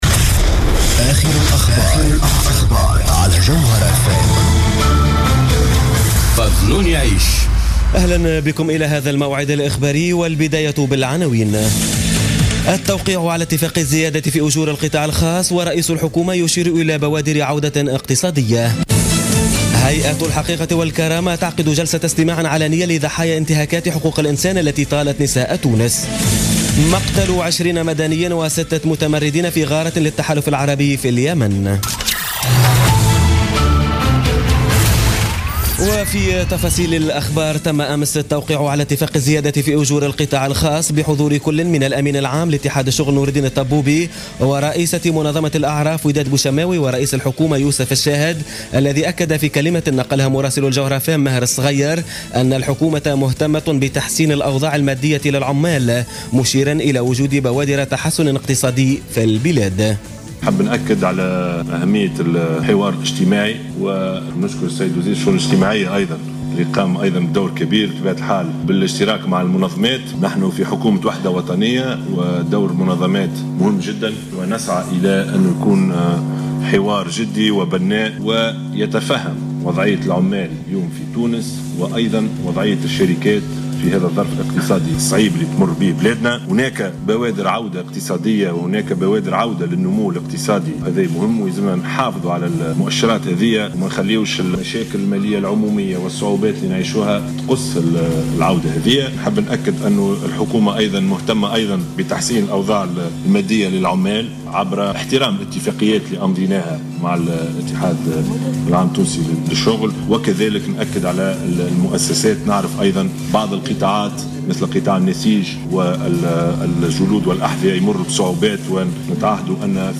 نشرة أخبار منتصف الليل ليوم السبت 11 مارس 2017